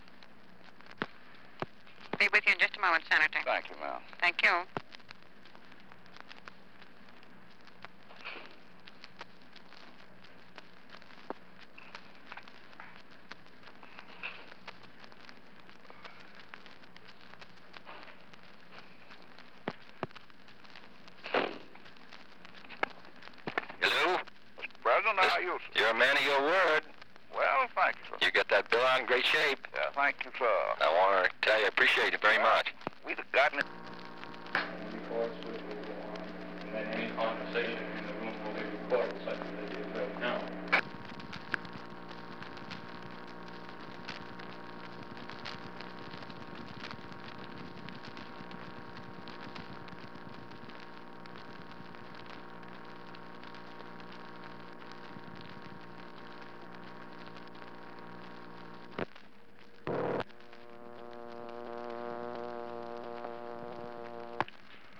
Conversation with James Eastland
Secret White House Tapes | John F. Kennedy Presidency Conversation with James Eastland Rewind 10 seconds Play/Pause Fast-forward 10 seconds 0:00 Download audio Previous Meetings: Tape 121/A57.